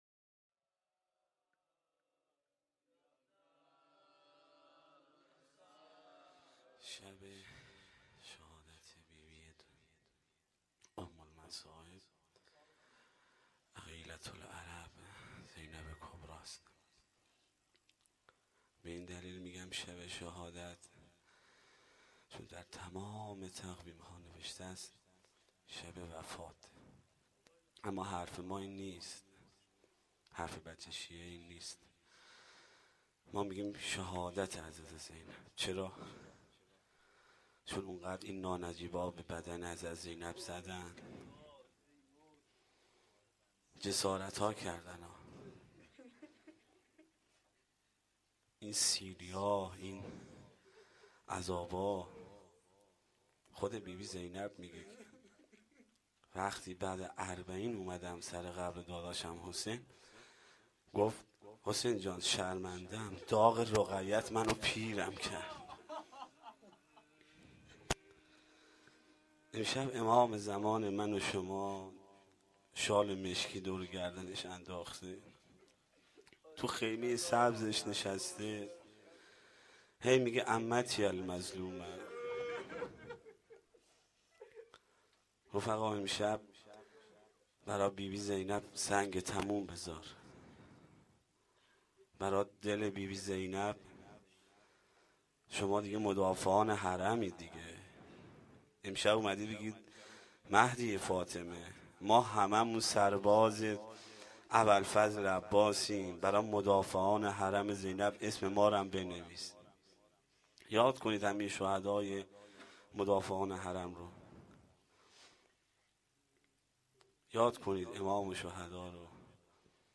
روضه العباس
roze.mp3